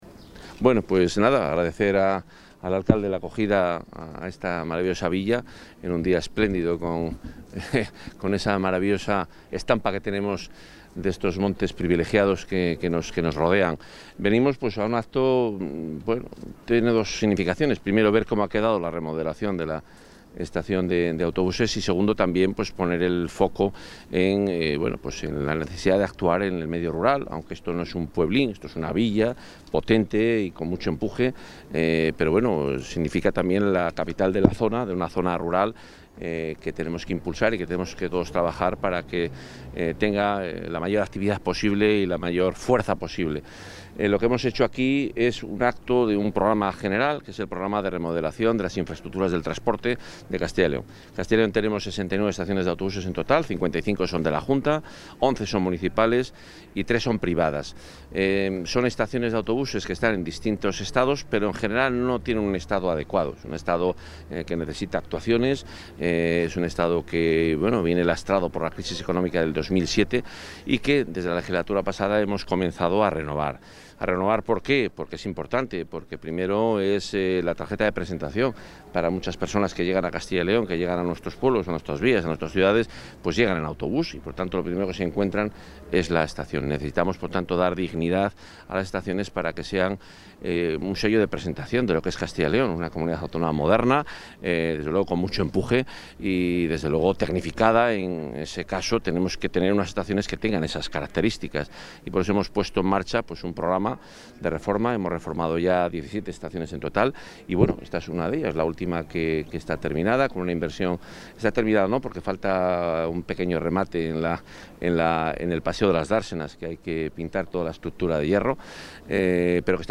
Intervención del consejero de Fomento y Medio Ambiente.
El consejero de Fomento y Medio Ambiente, Juan Carlos Suárez-Quiñones, ha visitado esta mañana la remodelación de la estación de autobuses de la localidad abulense de Arenas de San Pedro, en la que se han invertido 180.000 euros.